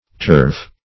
Turf \Turf\ (t[^u]rf), n.; pl. Turfs (t[^u]rfs), Obs. Turves